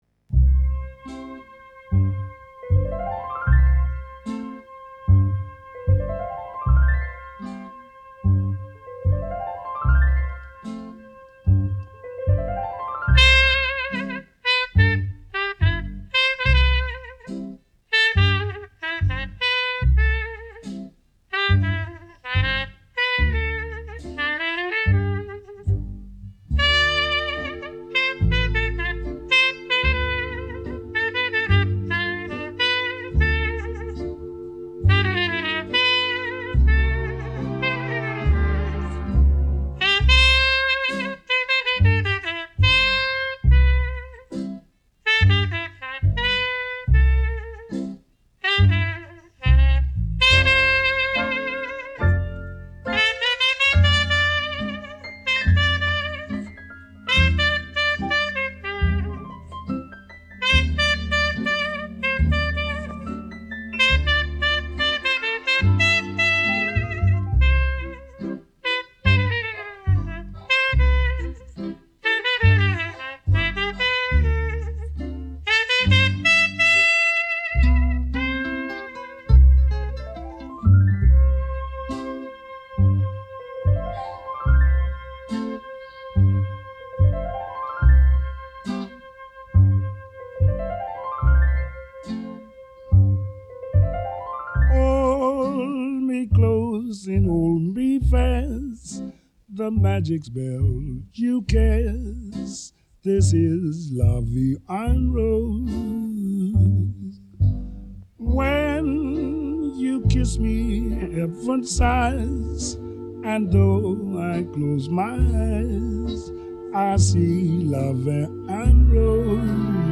(Trumpet Solo)
jazz music